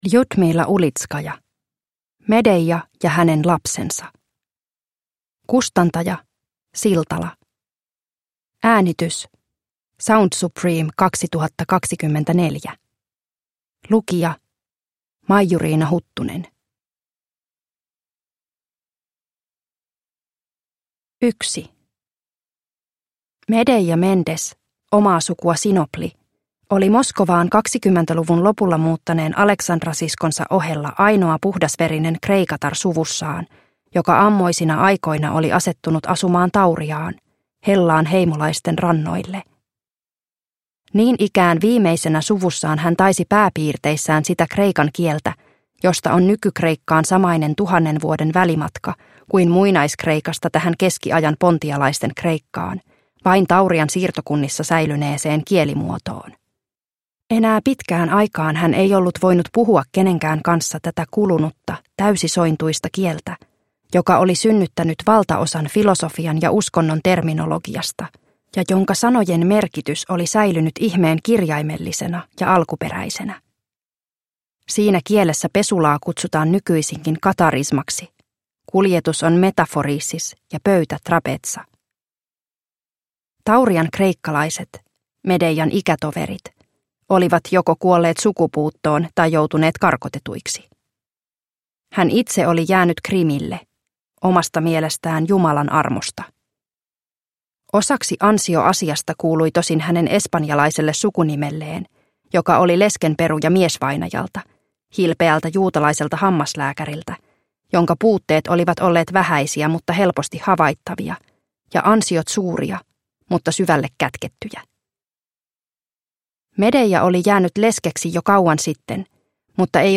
Medeia ja hänen lapsensa (ljudbok) av Ljudmila Ulitskaja